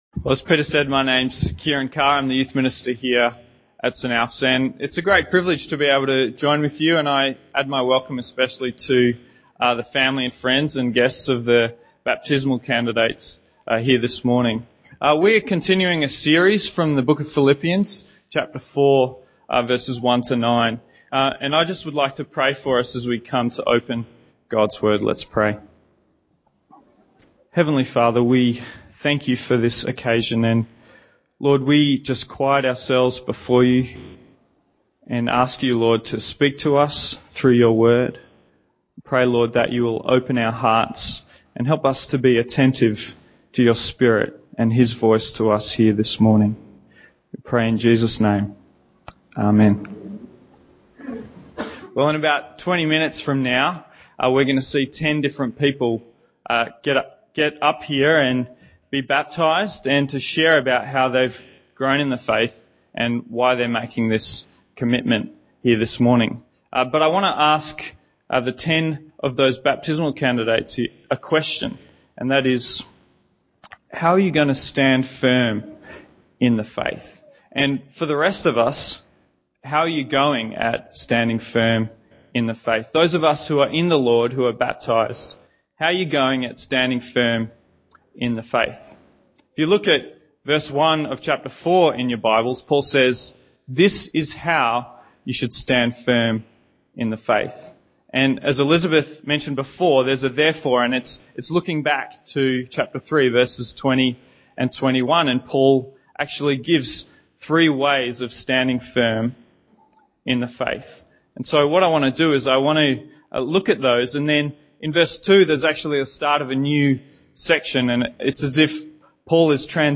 Trust Christ In this sermon